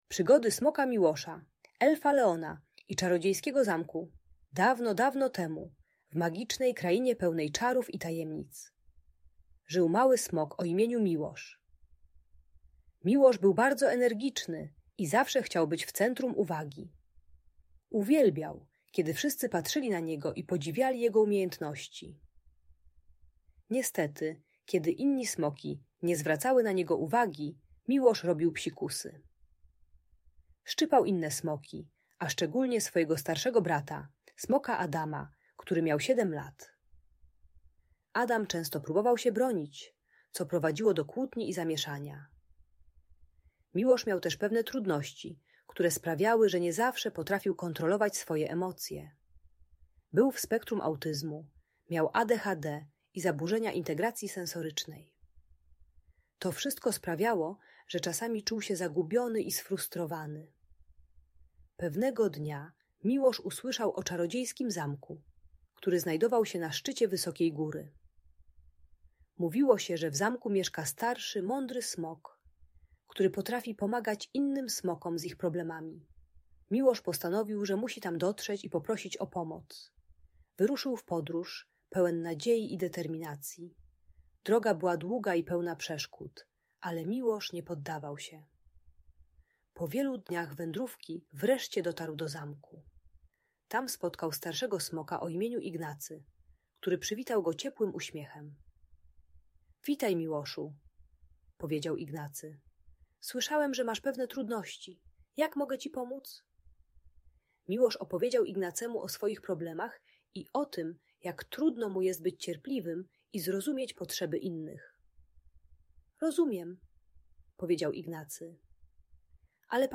Przygody Smoka Miłosza i Elfa Leona - Audiobajka